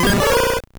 Cri d'Embrylex dans Pokémon Or et Argent.